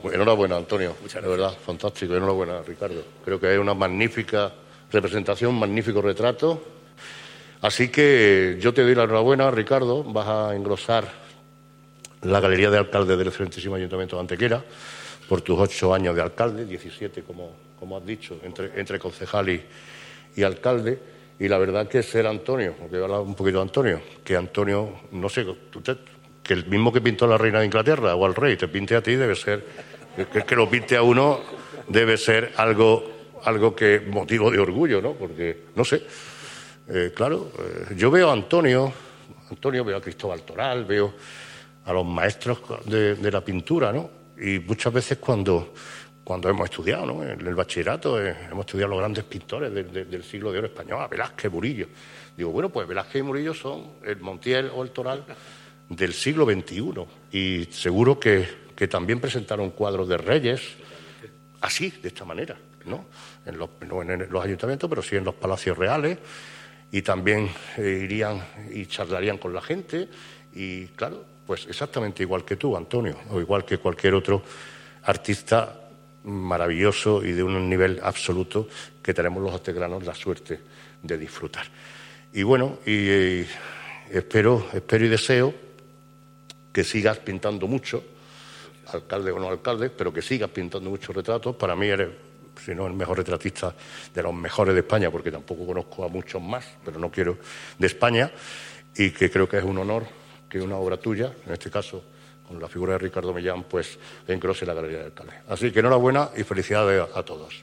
El alcalde de Antequera, Manolo Barón, ha presidido en la tarde de hoy jueves el acto de presentación del retrato del ex alcalde Ricardo Millán, primer edil antequerano entre los años 2003 y 2011.
Cortes de voz